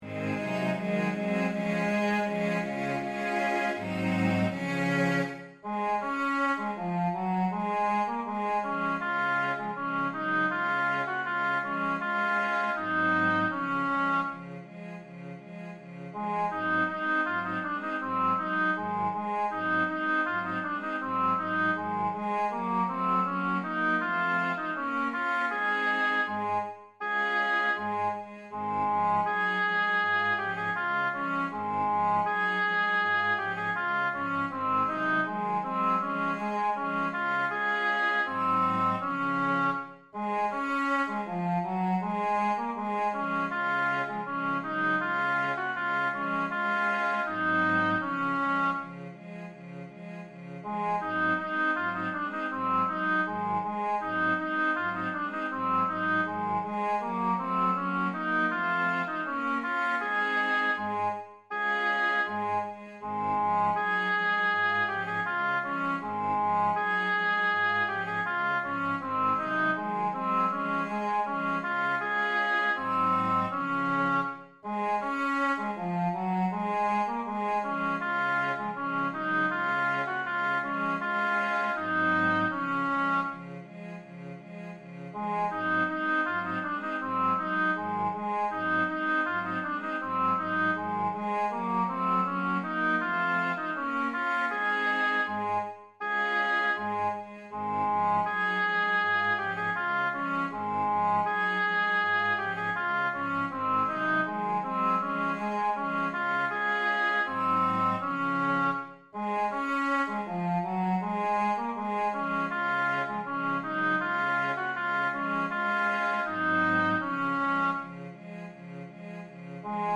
Das Wandern ist des Müllers Lust Tenor 1 als Mp3
das-wandern-ist-des-muellers-lust-ttbb-zoellner-einstudierung-tenor-1.mp3